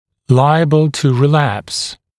[‘laɪəbl tu rɪ’læps][‘лайэбл ту ри’лэпс]склонный к рецидиву